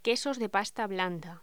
Locución: Quesos de pasta blanda
Sonidos: Voz humana